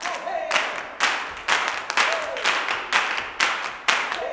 125GCLAPS3-L.wav